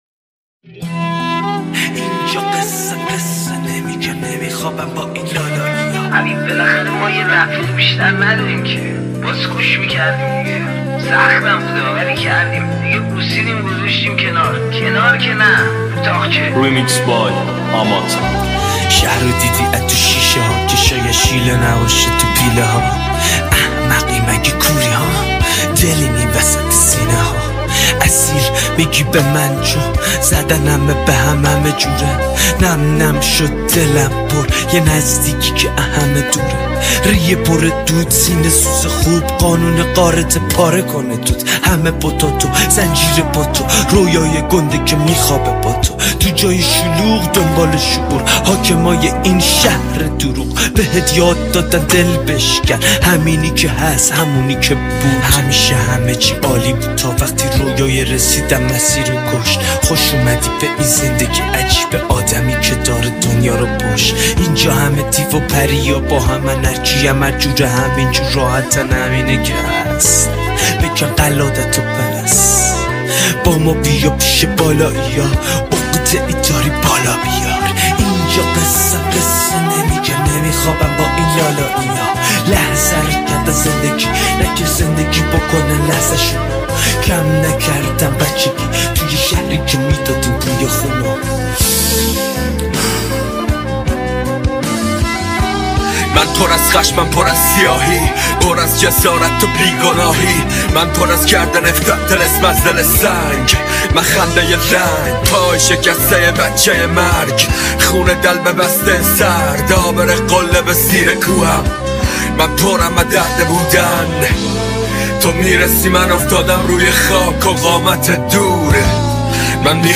ریمیکس گنگ
ریمیکس خفن رپی